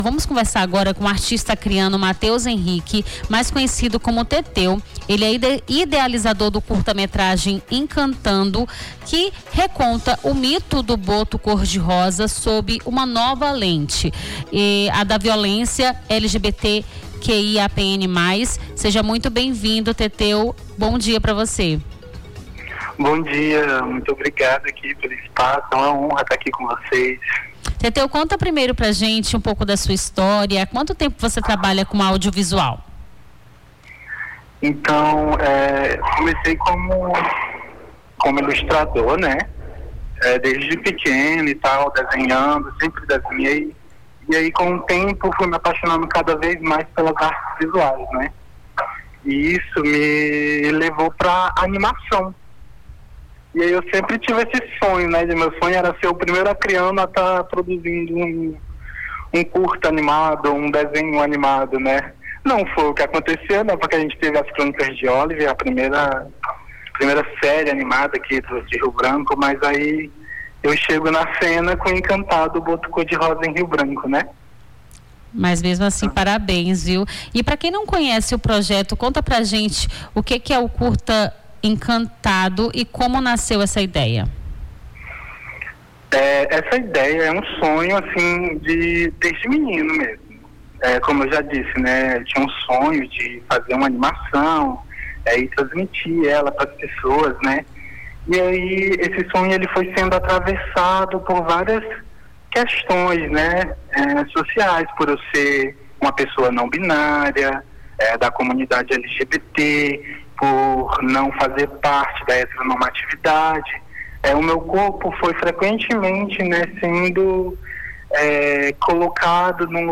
Nome do Artista - CENSURA - ENTREVISTA (CURTA METRAGEM ENCANTADO) 30-06-25.mp3